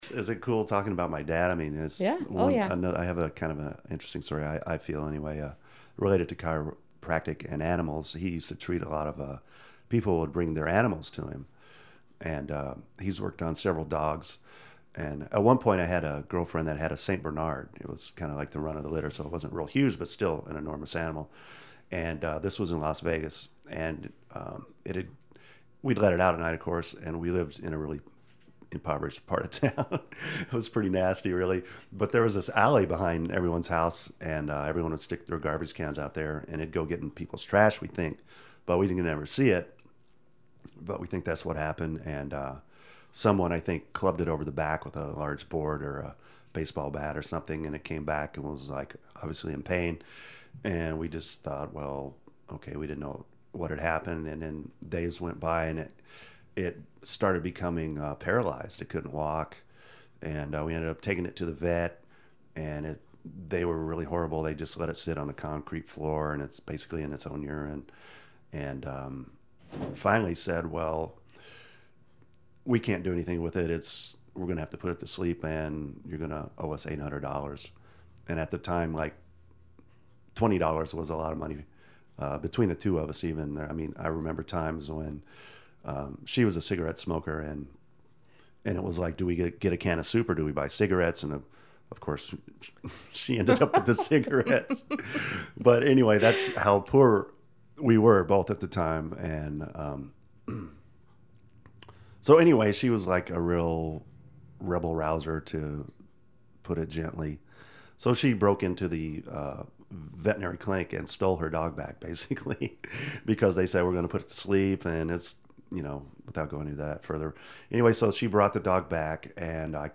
Unalaska, AK – The StoryCorps project records conversations between ordinary people about their lives.
Over the phone.